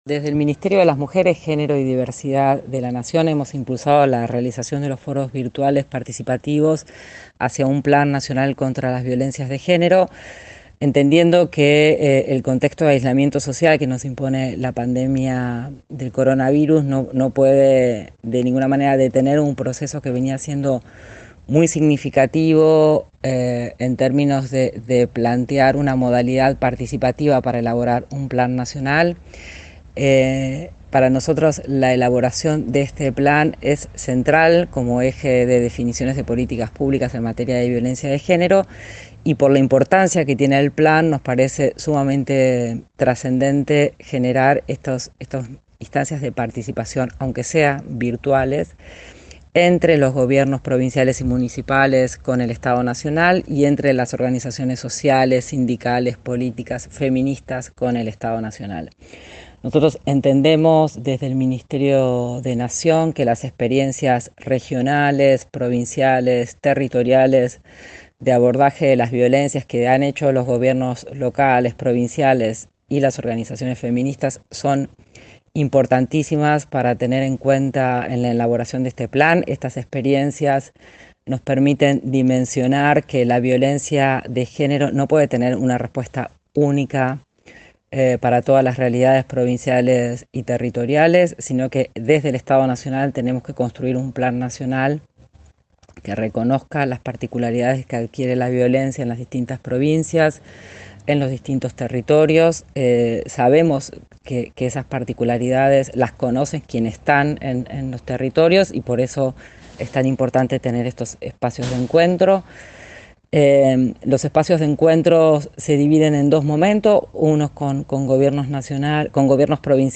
AUDIO | Laurana Malacalza habló sobre la realización entre febrero y abril de ocho foros federales
Laurana Malacalza, subsecretaria de Abordaje Integral de las Violencias por Razones de Género del Ministerio de las Mujeres, Géneros y Diversidad sobre los Foros Federales Participativos para la Formulación del Plan Nacional Contra las Violencias por Motivos de Género.